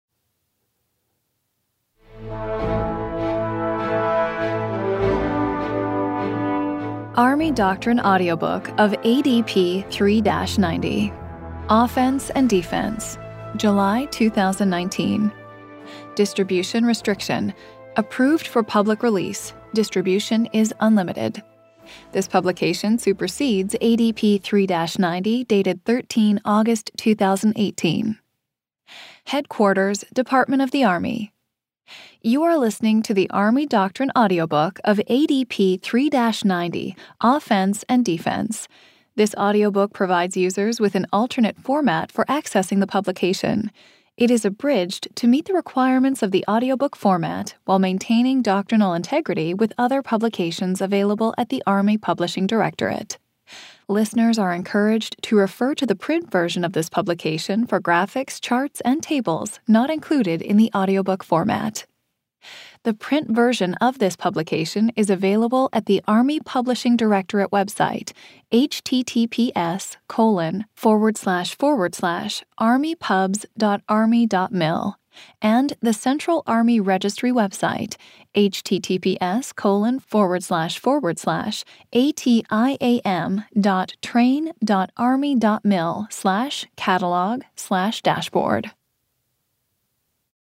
This is the download page for the Army Doctrine Audiobook of Army Doctrine Publication (ADP) 3-90, Offense and Defense
It has been abridged to meet the requirements of the audiobook format.